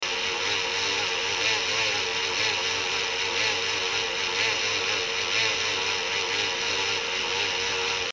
Now, I found myself awaken this morning at 0651 to a grinding sound from across the room - the laptop fan is failing again!
fan.mp3